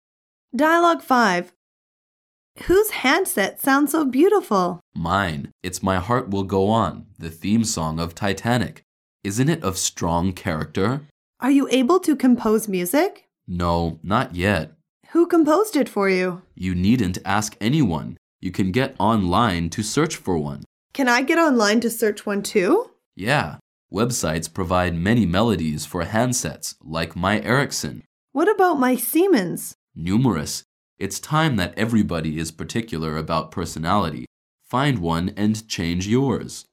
英语时尚口语情景对话2：手机新宠